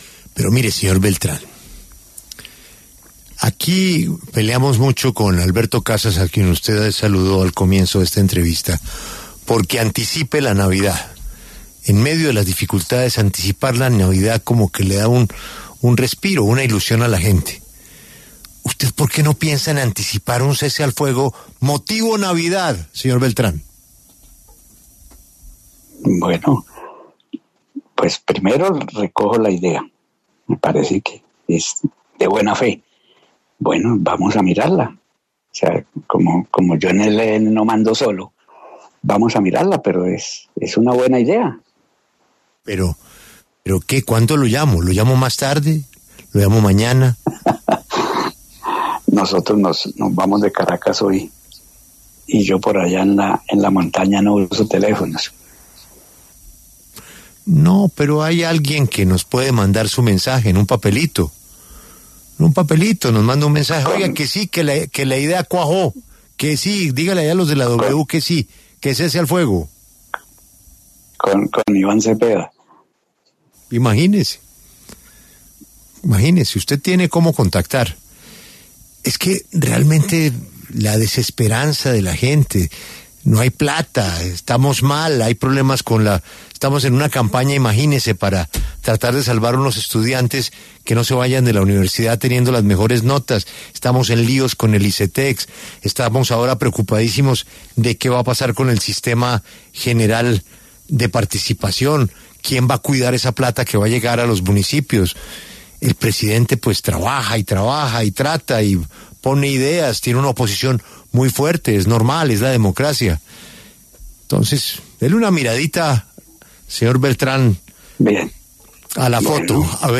El jefe negociador del ELN con el Gobierno Nacional, Pablo Beltrán, conversó con La W luego de que se conociera que ambas delegaciones retomarían los diálogos de paz.